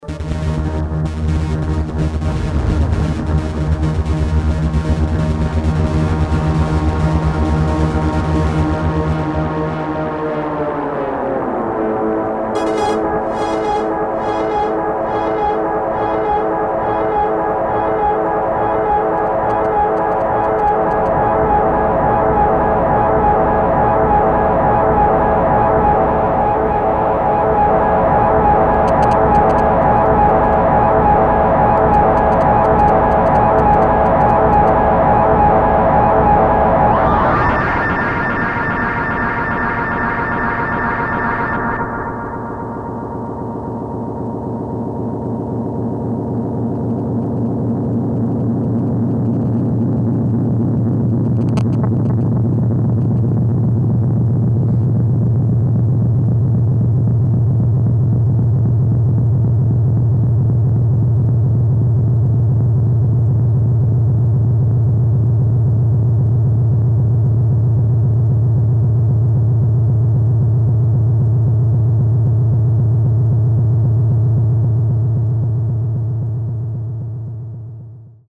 Analog Delay (Evans/Aria)
The Locobox is quite nice and warm, but sound really muffled even with full open filter.
demo delay test with Roland D50